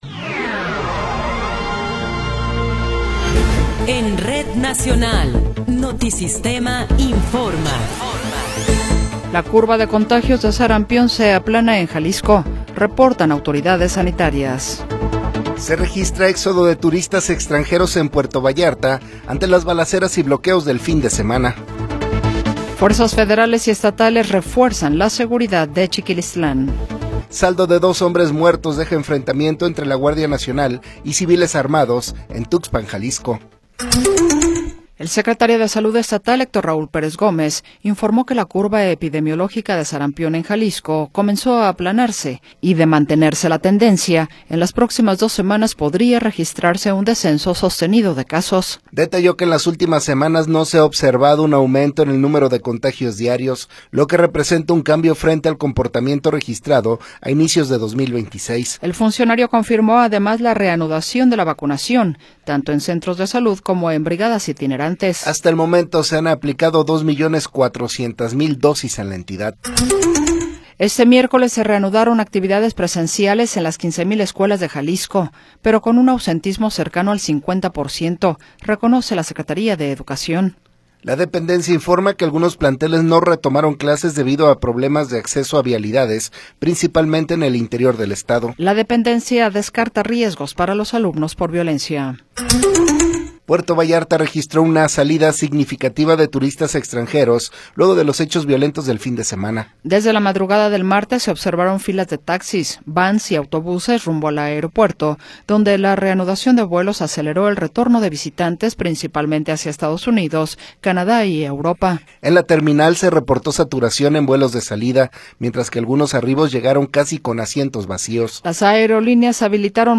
Noticiero 20 hrs. – 25 de Febrero de 2026